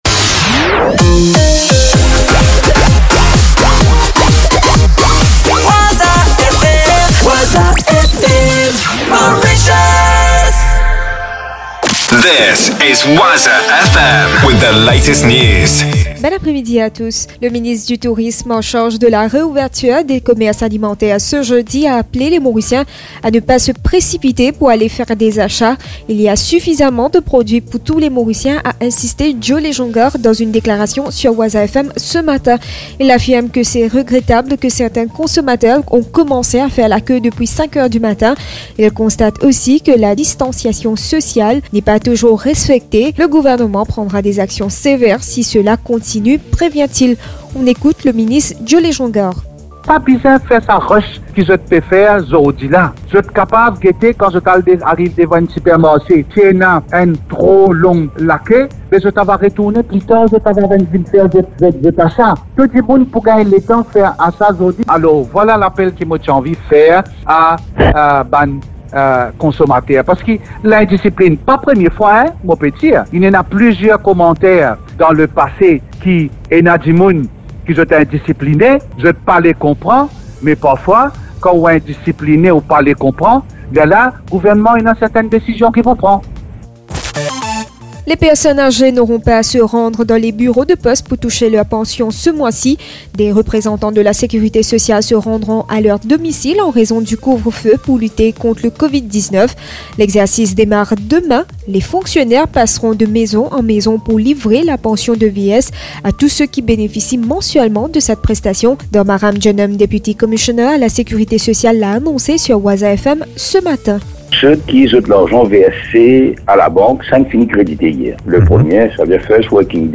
News 02.04.2020 18.00